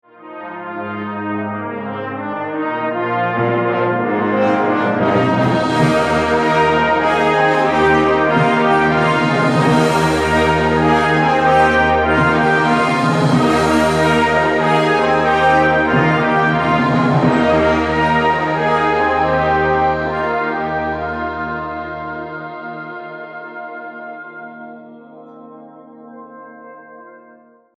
Description:Band music
Instrumentation:Brass band